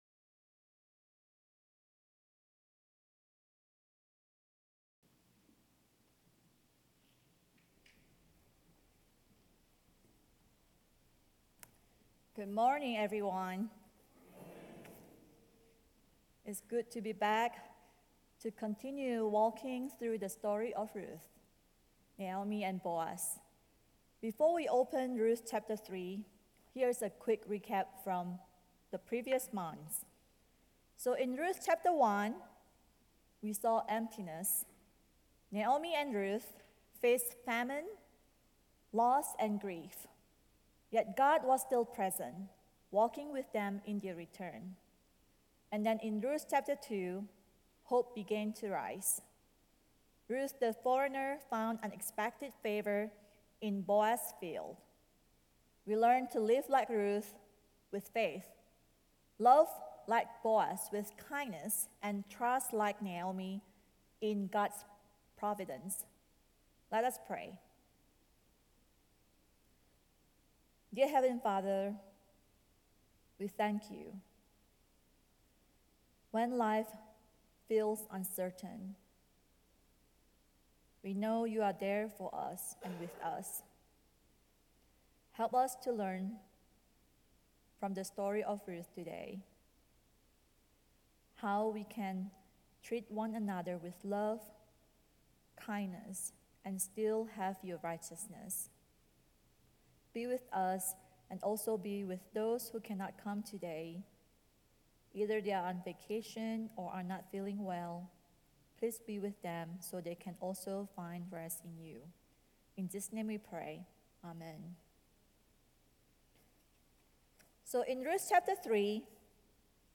A message from the series "Guest Speaker."